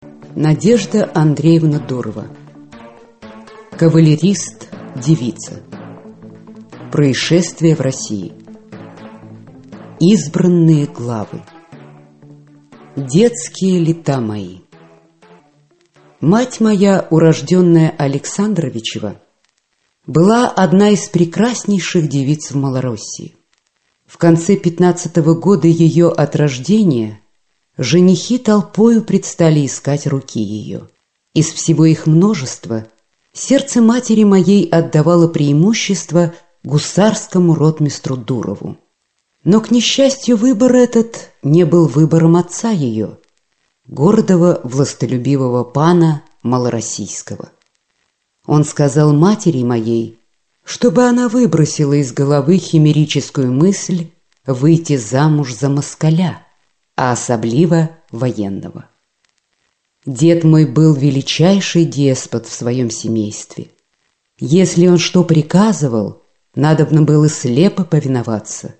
Аудиокнига Кавалерист – девица | Библиотека аудиокниг